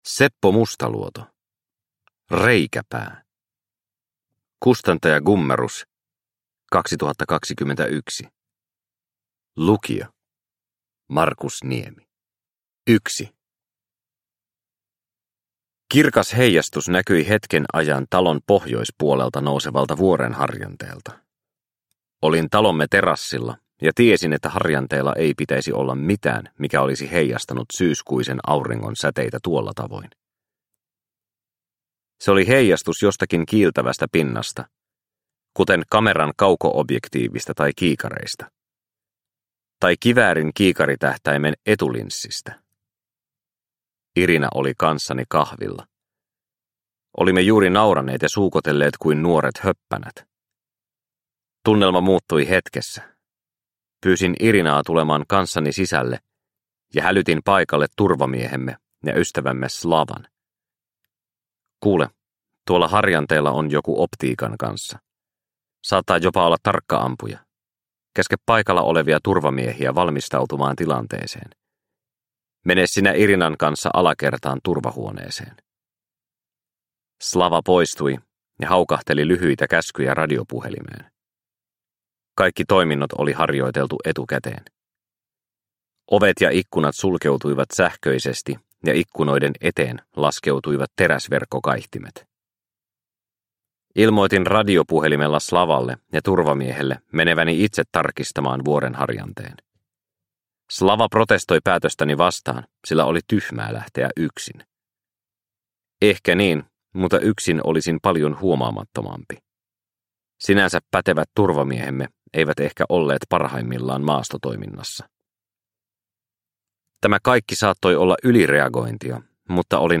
Reikäpää – Ljudbok – Laddas ner